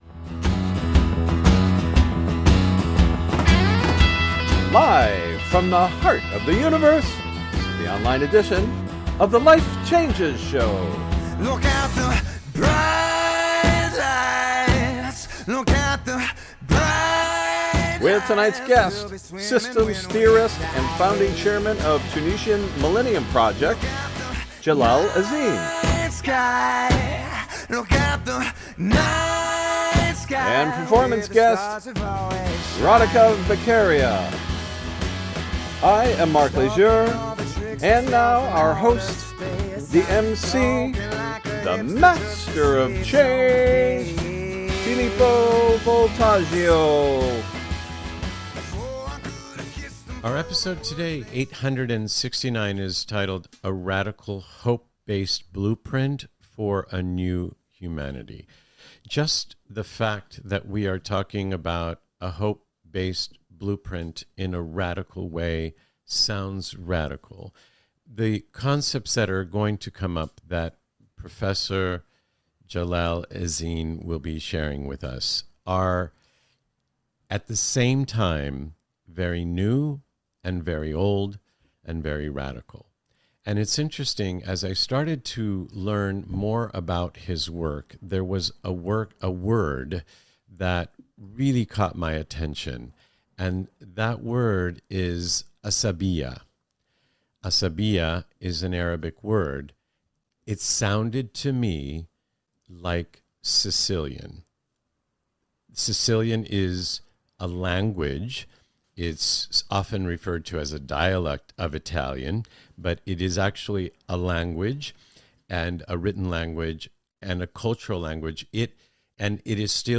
Interview Guest
Performance Guest